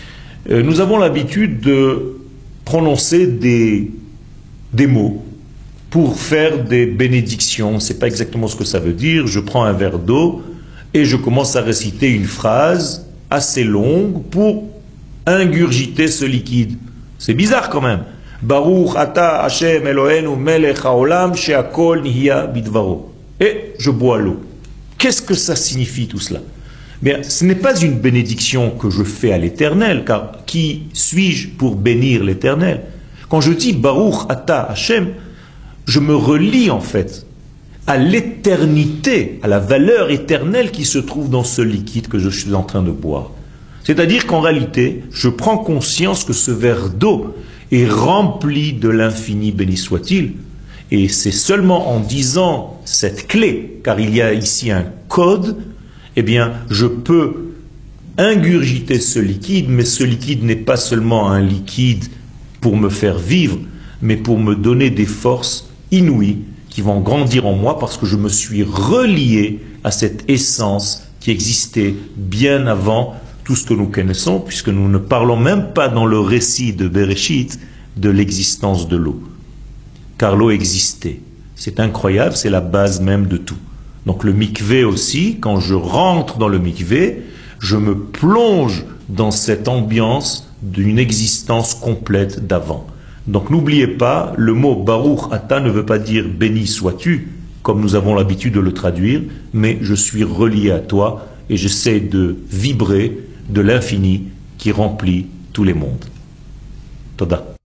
שיעור מ 06 מאי 2018
שיעורים קצרים